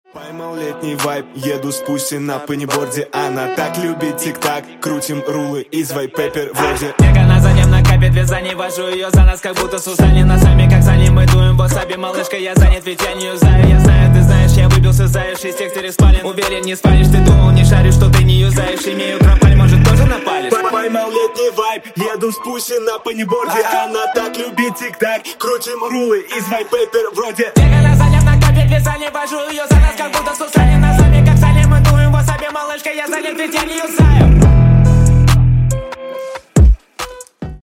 Громкие Рингтоны С Басами
Рэп Хип-Хоп Рингтоны